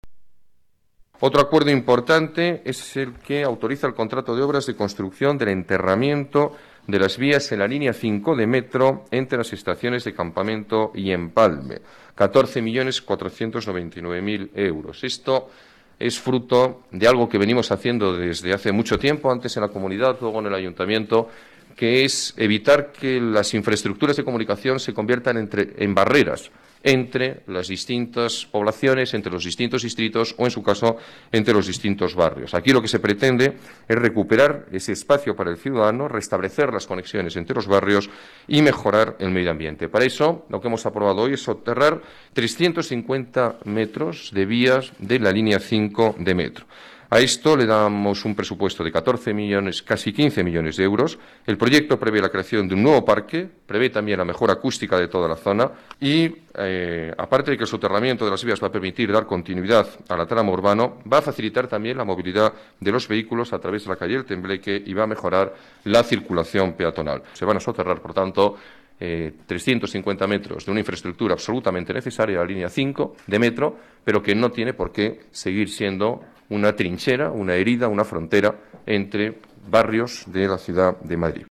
Nueva ventana:Declaraciones alcalde, Alberto Ruiz-Gallardón: soterramiento Línea 5 Metro